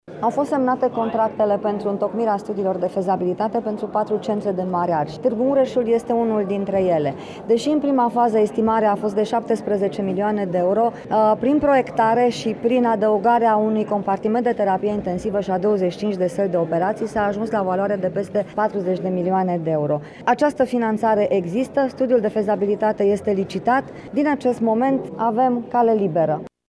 Așa a declarat, azi, la Tg.Mureș ministrul Sănătății Sorina Pintea: